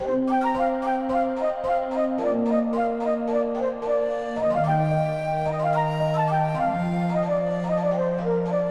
标签： FL 格斗 迷幻 中世纪 trap 循环 嘻哈 自由
声道立体声